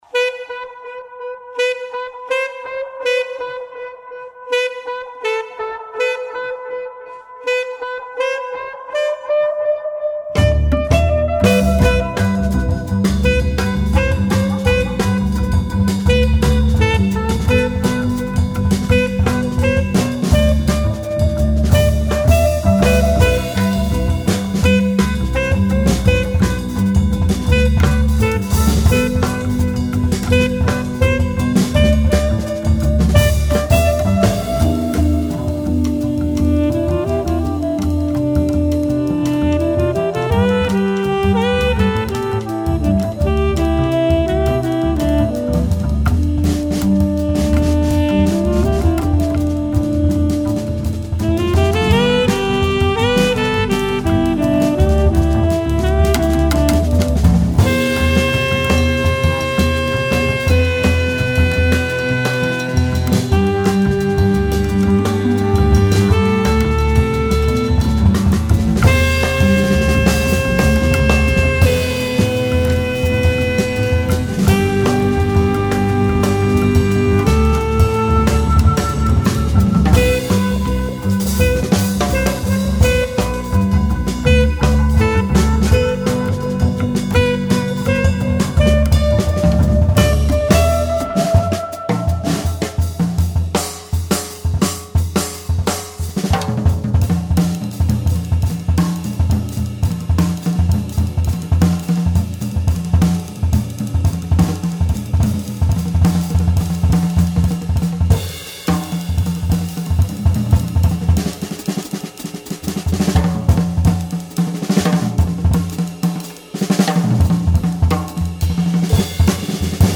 For electronic effects and jazz